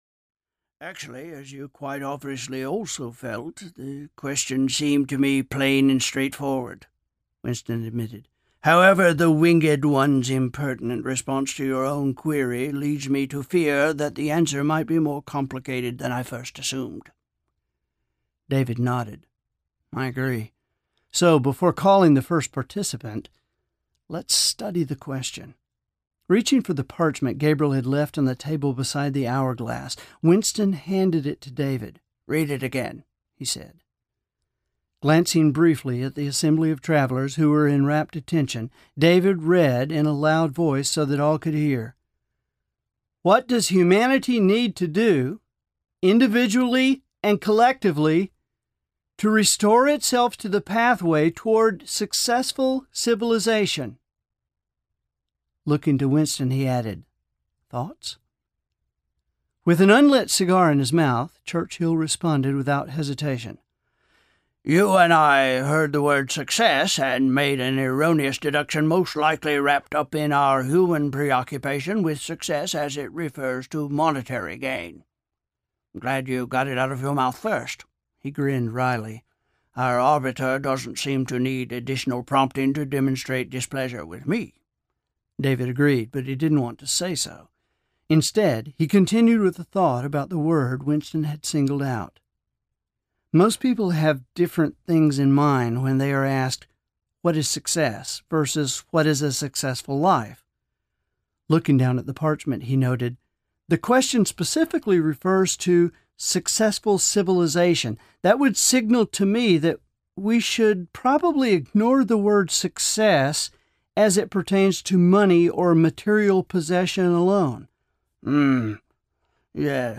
The Final Summit Audiobook